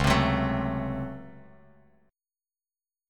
DbM7sus4#5 chord